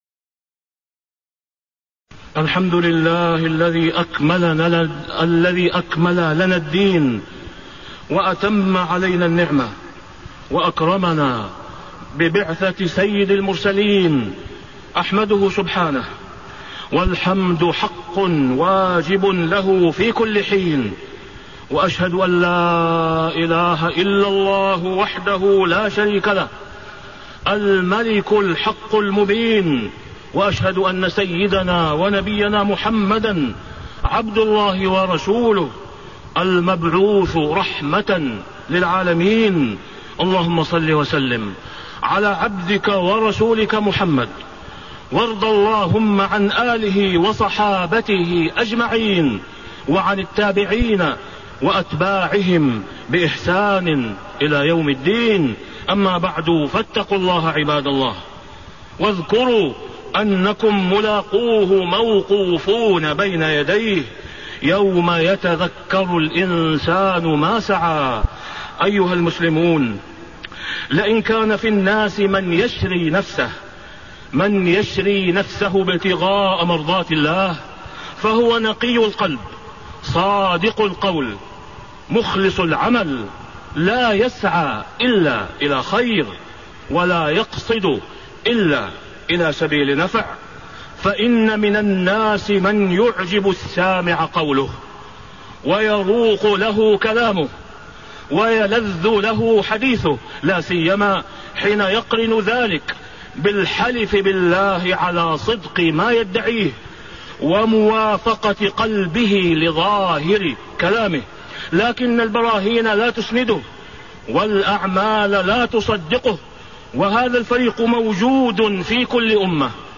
تاريخ النشر ٣٠ جمادى الآخرة ١٤٣٤ هـ المكان: المسجد الحرام الشيخ: فضيلة الشيخ د. أسامة بن عبدالله خياط فضيلة الشيخ د. أسامة بن عبدالله خياط الصلة بين اللسان والقلب والجوارح